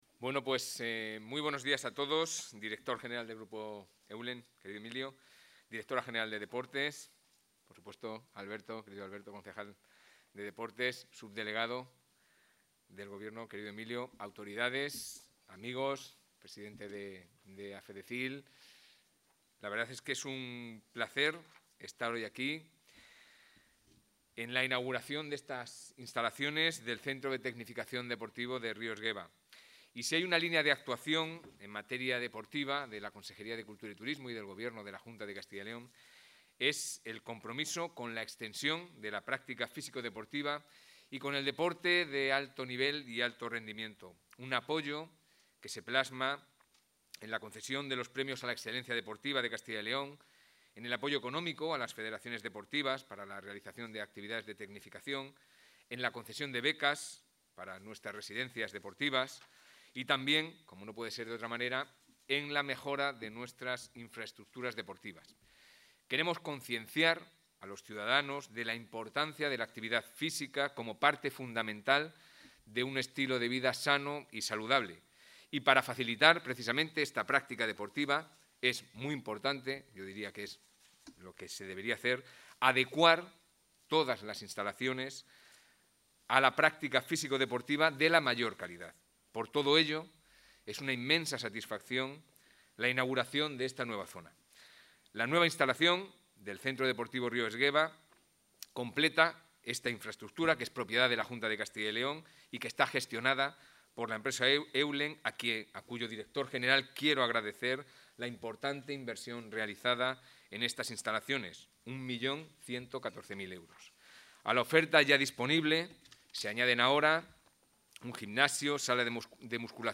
Audio consejero.
El consejero de Cultura y Turismo, Javier Ortega, ha participado hoy en la inauguración de la nueva zona deportiva del Centro Deportivo Río Esgueva, en Valladolid.